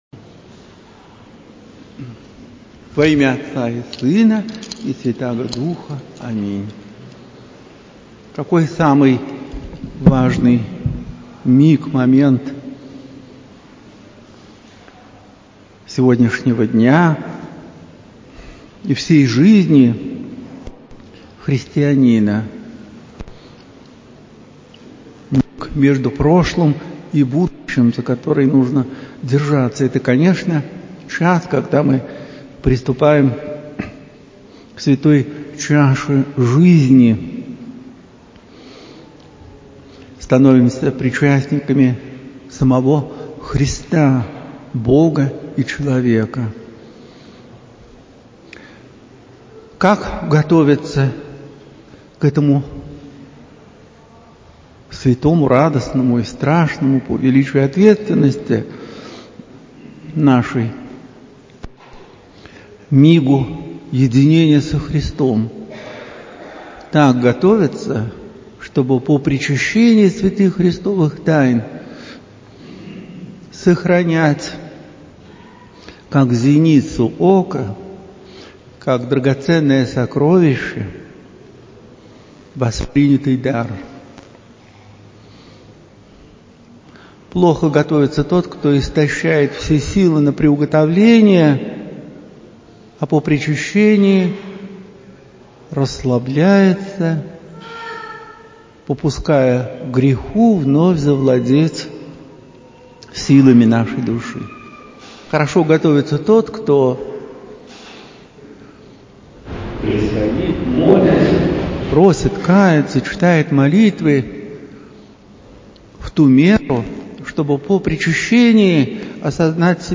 Проповедь протоиерея Артемия Владимирова на Евангелие от Матфея, гл. IX, ст. 27-35. В храме Алексея человека Божия ставропигиального женского Алексеевского монастыря. На литургии, 23 июля 2023.